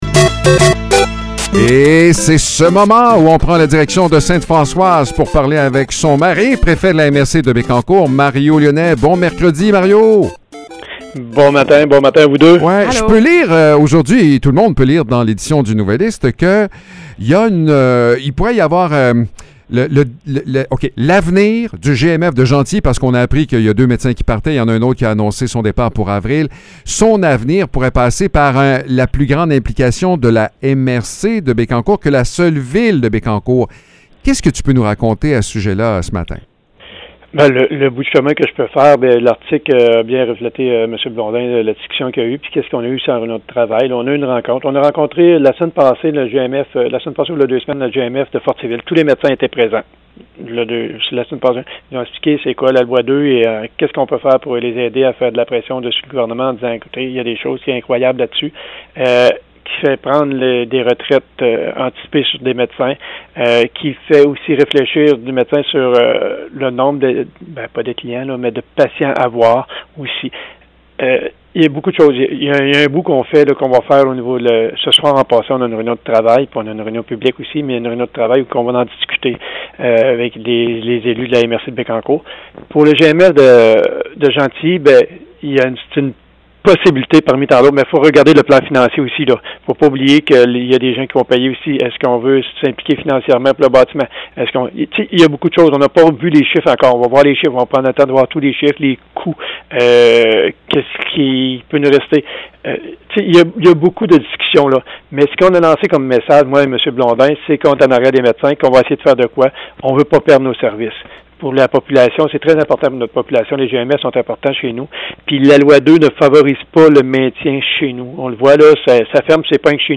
Mario Lyonnais, maire de Sainte-Françoise et préfet de la MRC de Bécancour, nous parle de l’avenir du Groupe de Médecine Familiale de Bécancour. Les défaillances annoncées par Hydro-Québec forcent la région à envisager rapidement des solutions. Le territoire réfléchit aussi à des projets de déboisement afin d’optimiser les terres destinées à la culture.